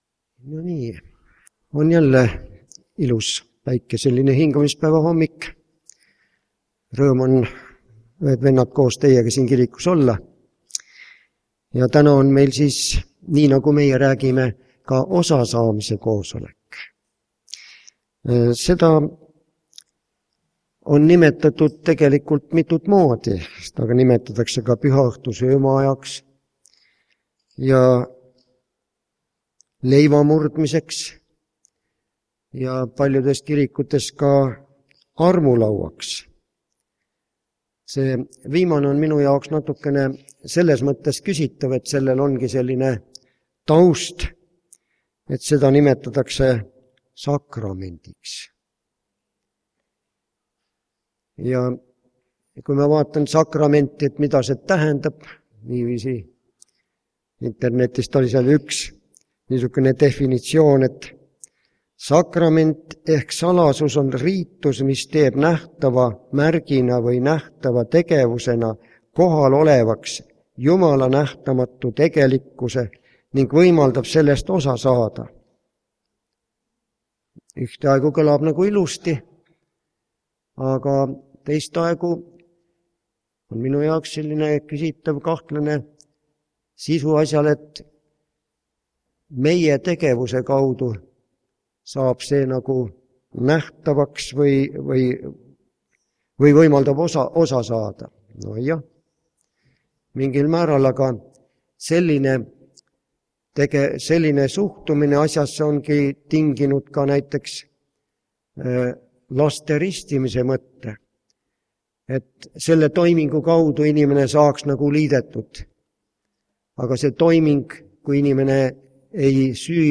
Tänase jutluse teemaks on OSASAAMINE Vahel nimetatakse seda ka pühaks sakramendiks või armulauaks... aga mis see teenistus tegelikult on?!